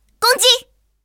野牛开火语音2.OGG